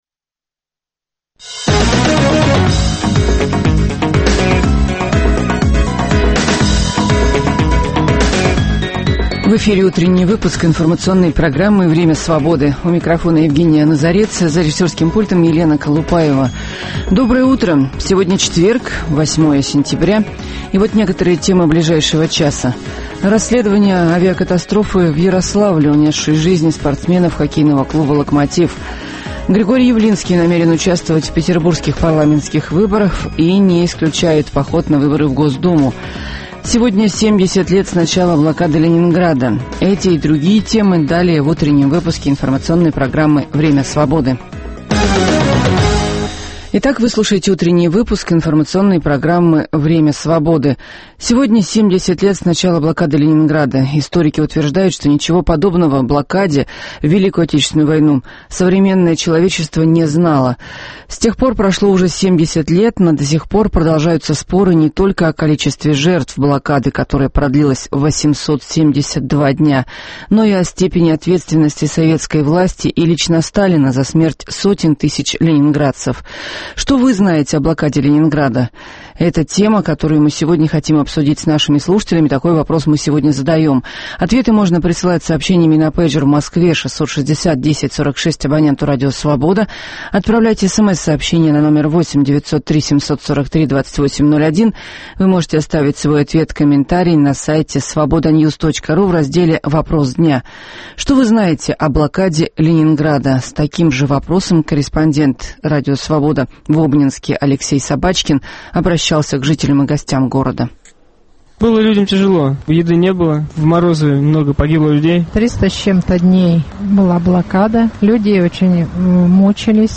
Перспективы и подробности первых событий дня наступившего, дискуссии с экспертами на актуальные темы, обсуждение вопроса дня со слушателями в прямом эфире.